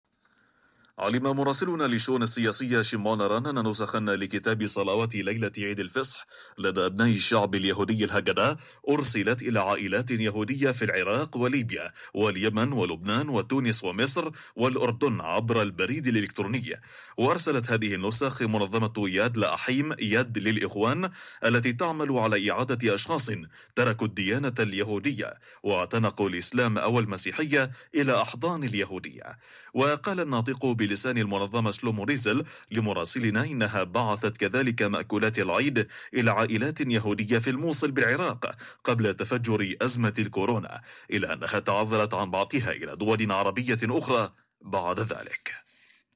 אנשי מחלקה החדשות של 'קול ישראל' בשפה הערבית ששמעו על הנושא החליטו לפרסם על כך אייטם במהדורת החדשות של הרדיו שמואזנת על ידי מאות אלפי ערבים ישראלים וכן על ידי עשרות אלפי מוסלמים במדינות ערב.